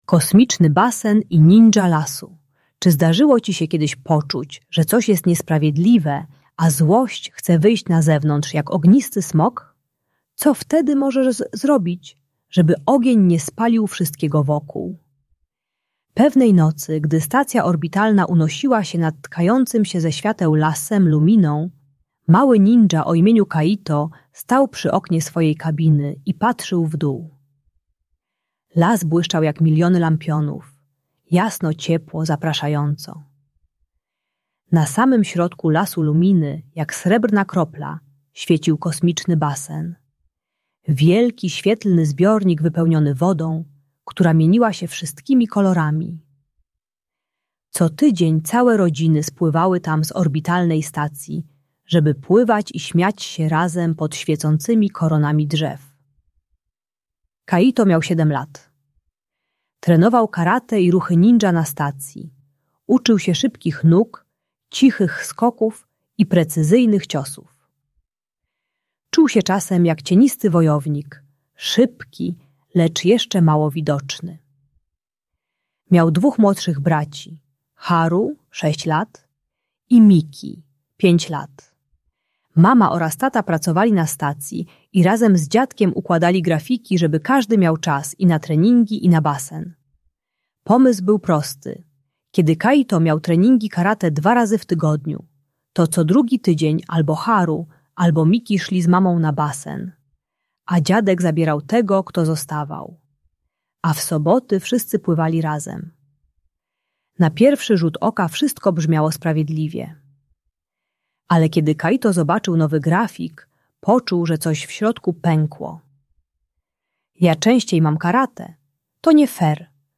Kosmiczny Basen i Ninja Lasu - Bunt i wybuchy złości | Audiobajka